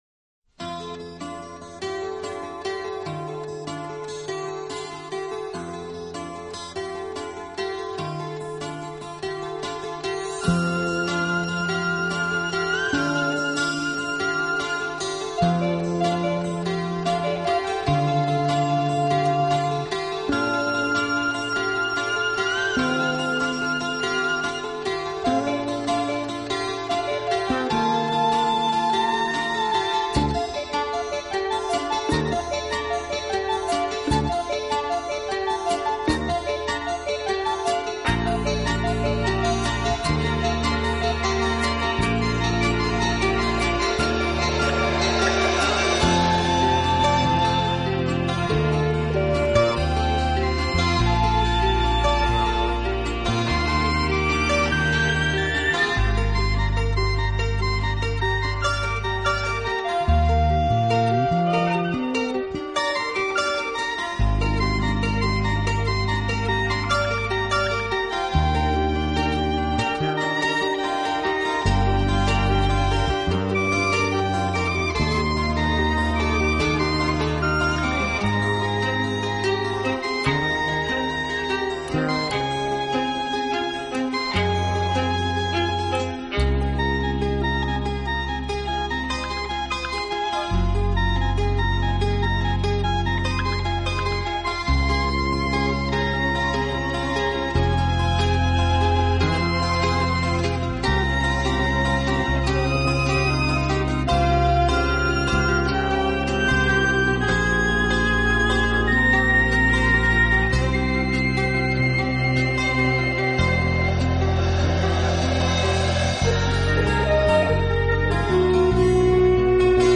让人赏心悦目、精神松弛的感觉，实在难得。